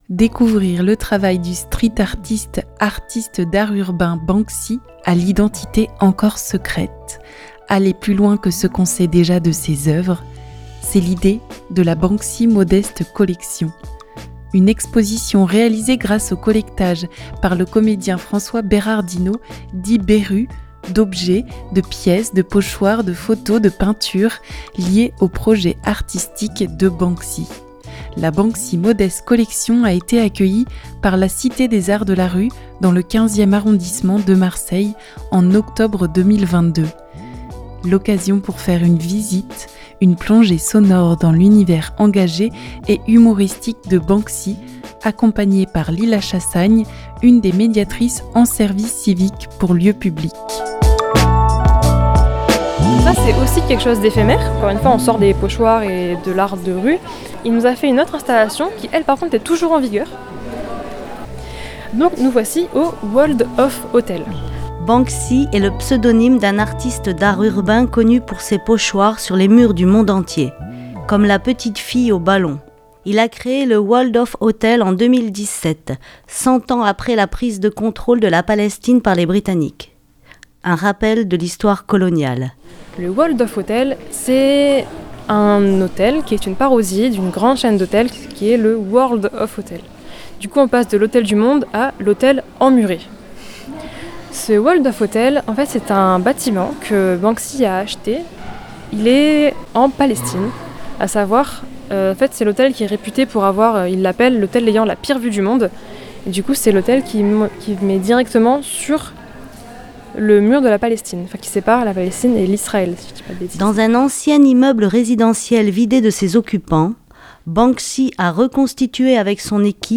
La Banksy Modeste Collection a été accueillie par la Cités des Arts de la Rue dans le 15ème arrondissement de Marseille en octobre 2022.